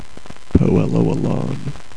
The Poelo Elon (po·EHL·o EHL·ahn)